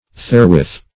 Therewith \There*with"\, adv.